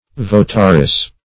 Votaress \Vo"ta*ress\